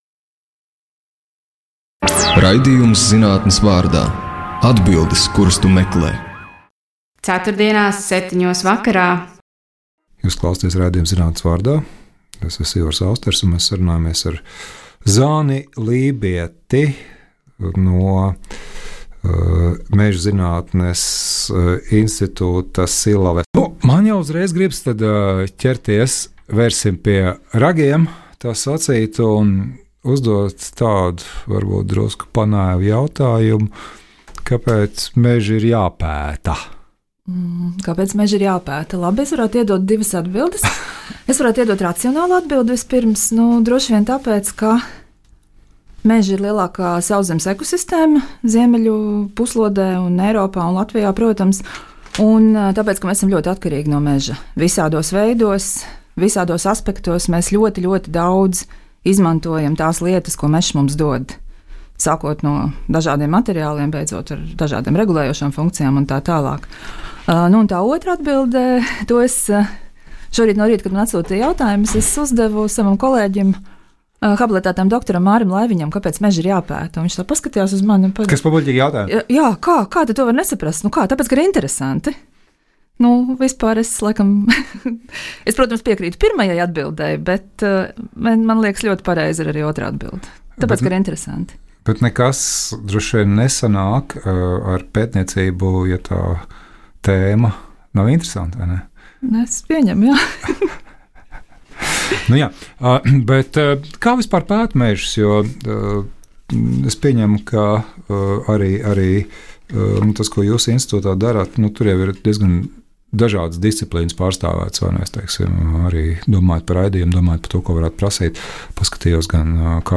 Raidījums iepazīstina klausītājus ar Latvijas zinātnieku pētījumiem, eksperimentiem un jaunatklājumiem. Raidījumā tiek iztaujāti zinātnieki par viņu akadēmisko un praktisko ikdienu – lekciju sagatavošanu un pasniegšanu, studentu darbu vadīšanu, sadarbību ar valsts institūcijām vai privātajiem partneriem.